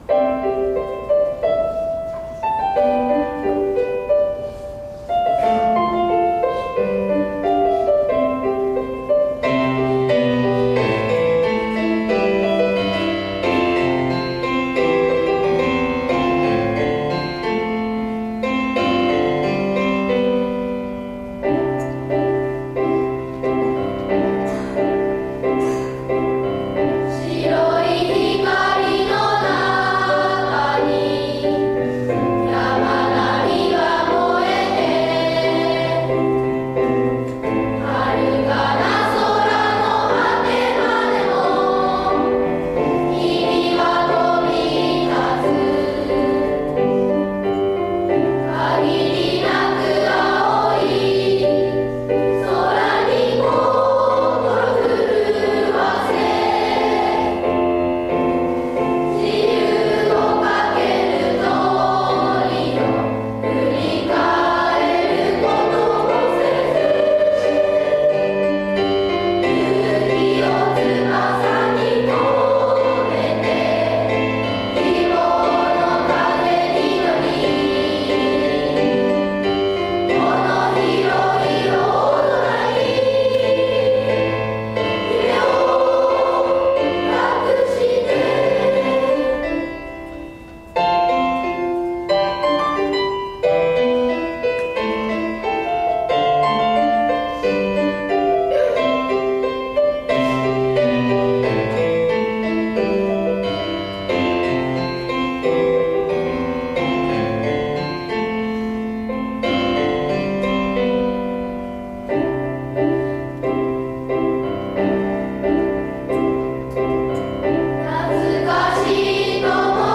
卒業式で歌唱予定の「旅立ちの日に」の歌をＨＰにアップしました。 ２年前の卒業生の歌声です。